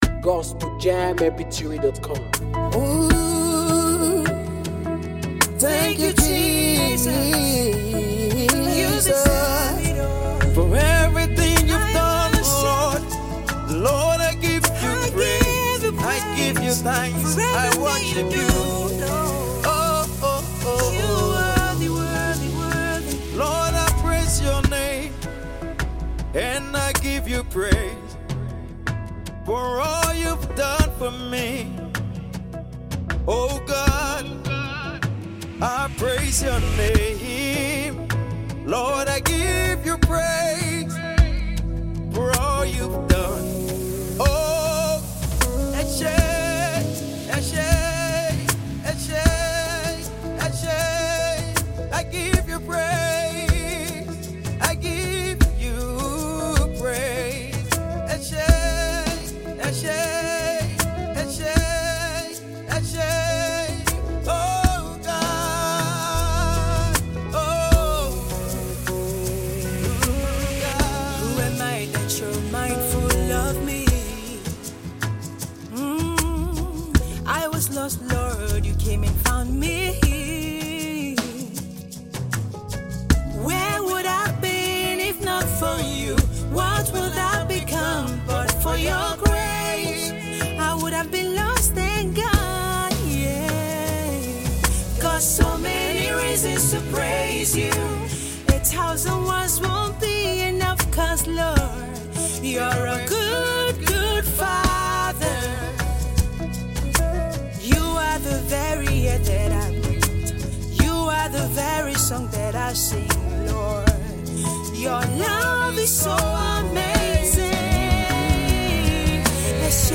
music
a Nigerian Gospel singer and a worship leader
a powerful sound of Thanksgiving